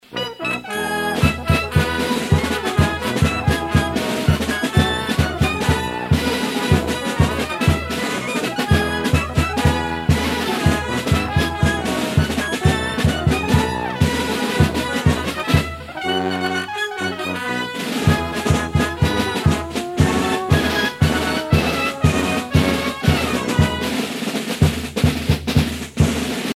circonstance : carnaval, mardi-gras ;
Pièce musicale éditée